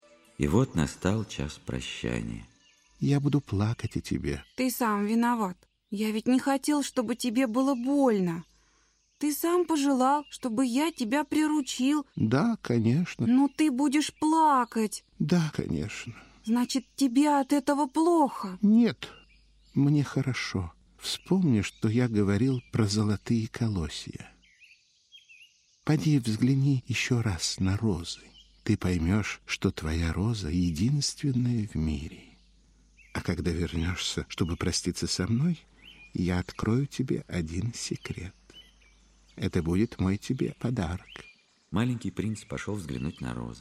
Аудиокнига Маленький Принц